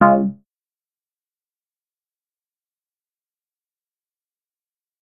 modalert.ogg